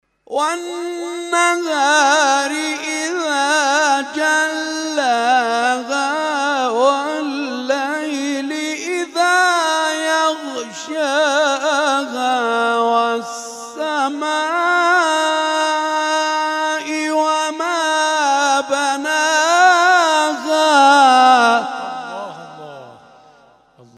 محفل انس با قرآن در آستان عبدالعظیم(ع)
قطعات تلاوت